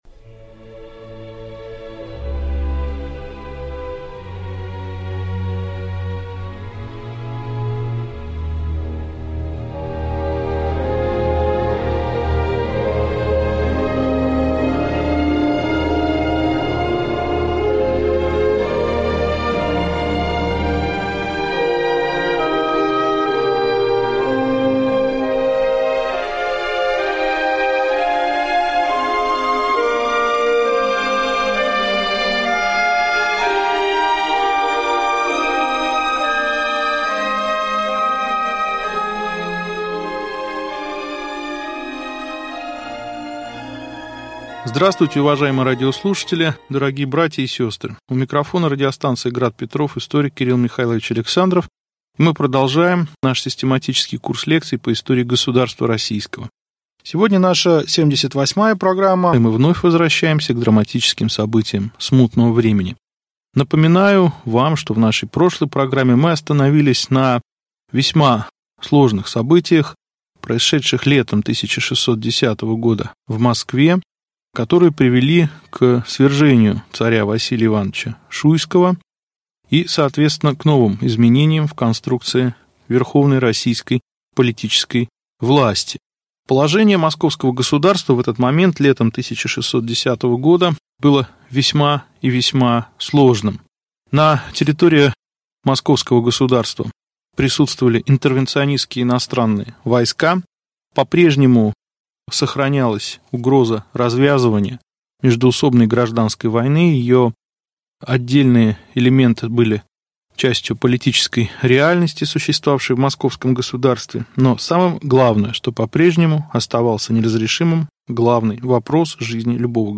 Аудиокнига Лекция 78. Смута. Семибоярщина. Начало народно-патриотического движения | Библиотека аудиокниг